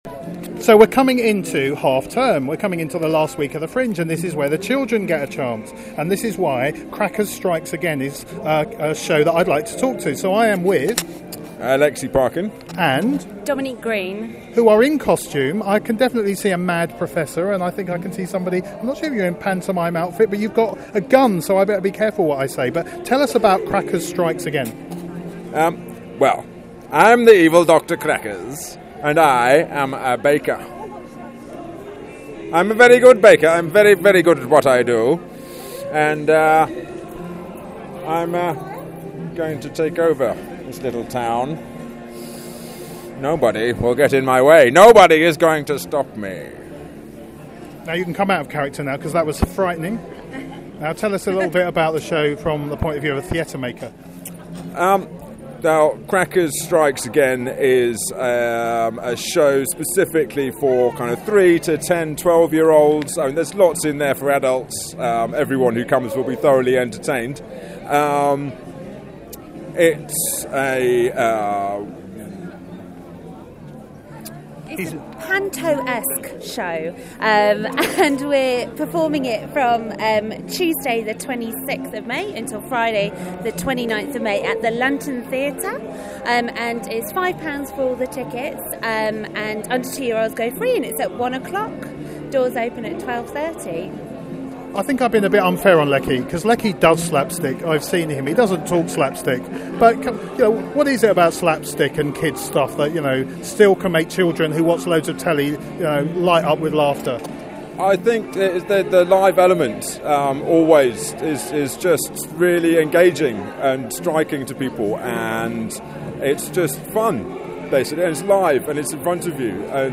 Whizz Bang Pop Productions do parties and they also do theatre. Here the cast, in costume, talk about their interactive children's show, Crackers Strikes Again. Listen to our interview with Crackers Strikes Again